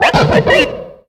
Cri de Baggaïd dans Pokémon X et Y.